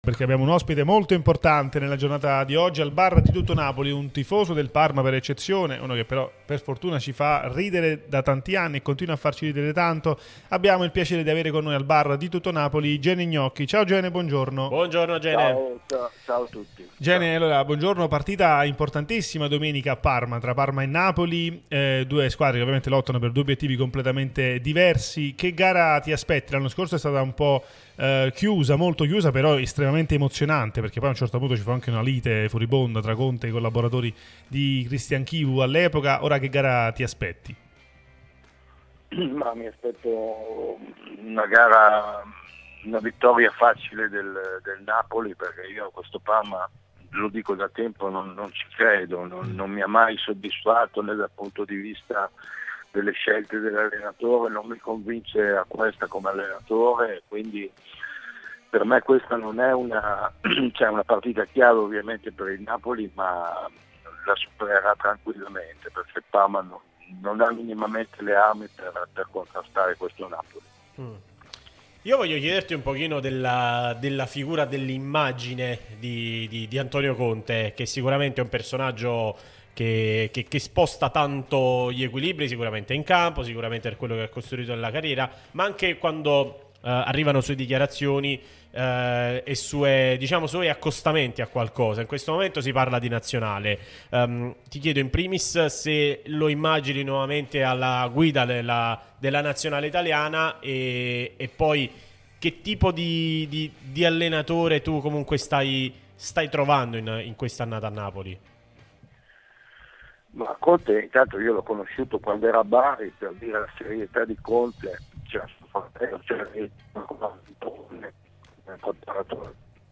Gene Gnocchi è intervenuto su Radio Tutto Napoli, l'unica radio tutta azzurra e live tutto il giorno